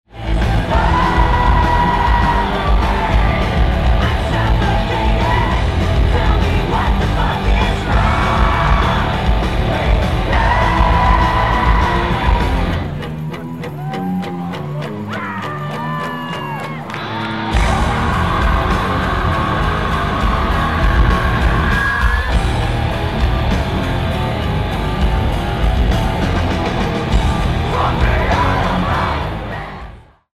Venue:New England Dodge Music Center
Venue Type:Amphitheatre
Strong distortion on the heavier songs.